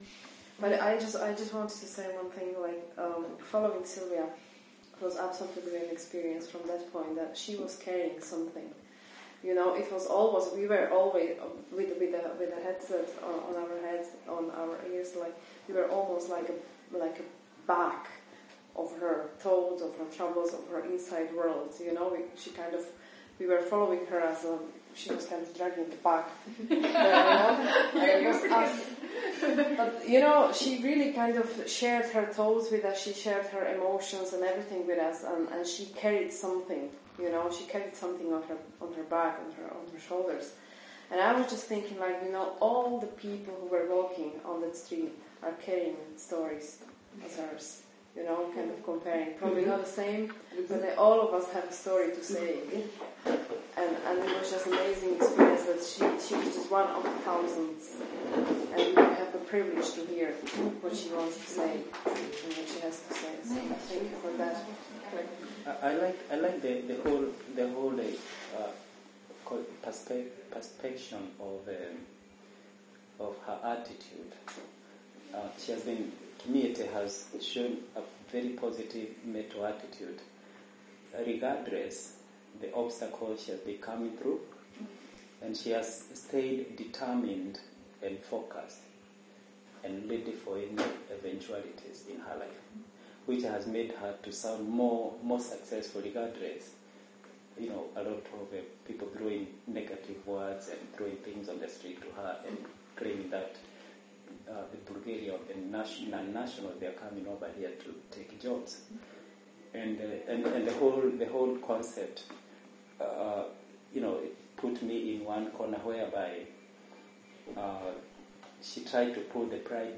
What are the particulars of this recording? Audio highlights from Riverbank Newbridge Meet the Makers.mp3